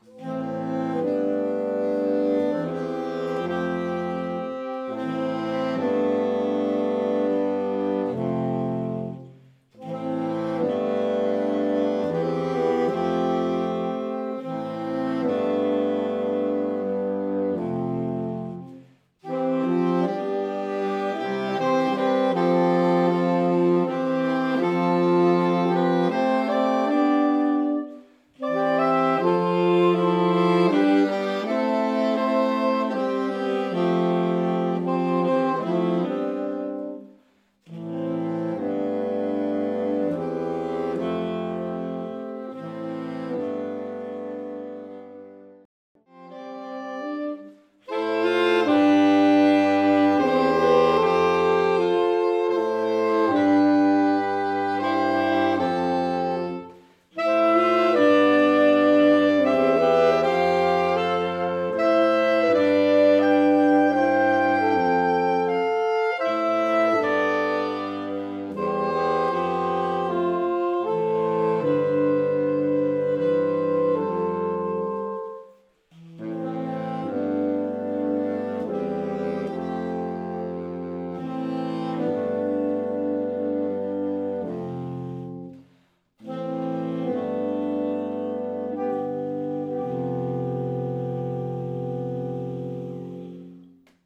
Einige Titel sind mit Hörbeispielen hinterlegt (MP3), die mit kleiner Technik und live aufgenommen wurden. Diese Beispiele sind durch Ein- bzw. Ausblendungen gekürzt.
Traditionals